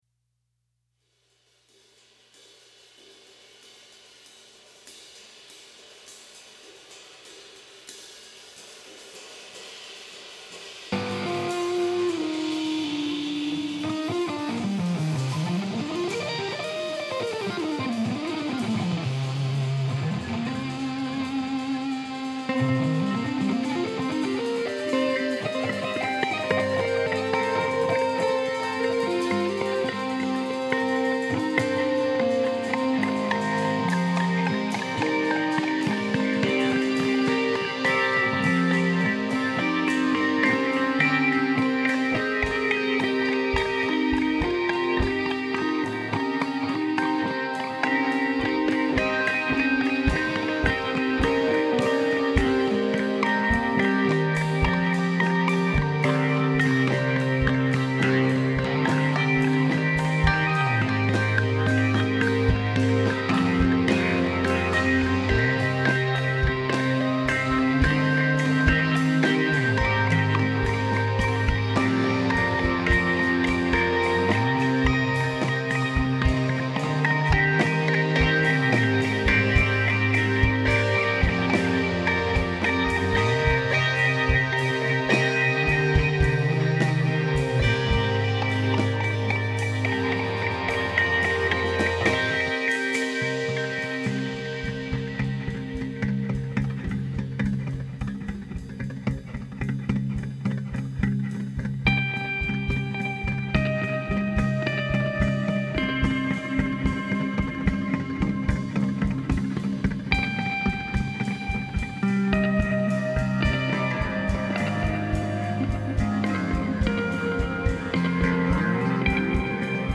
an instrumental trio
Warr guitar
drums